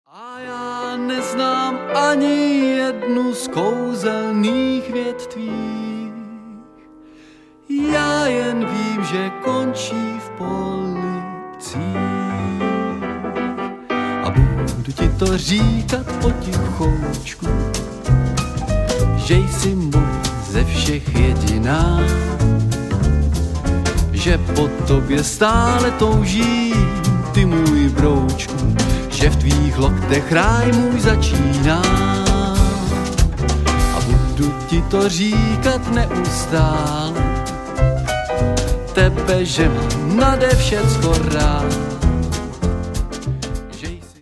Hrajeme živý jazz, swing